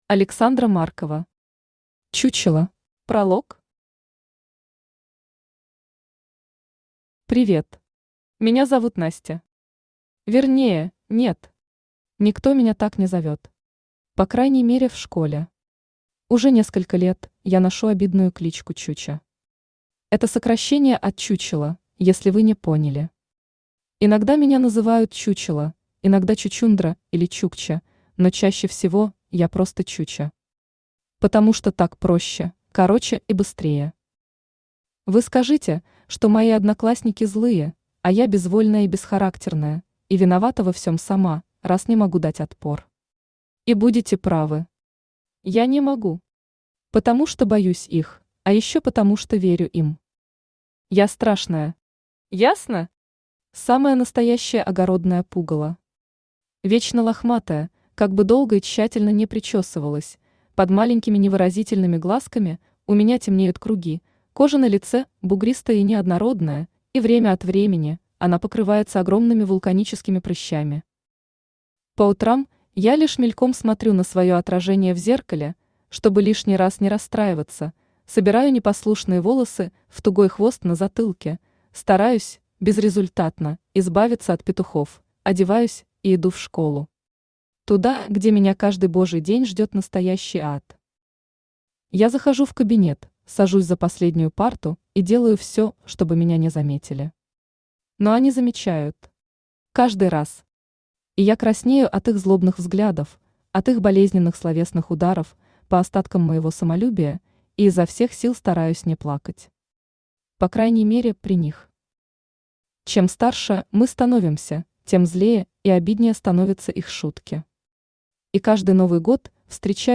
Аудиокнига Чучела | Библиотека аудиокниг
Aудиокнига Чучела Автор Александра Маркова Читает аудиокнигу Авточтец ЛитРес.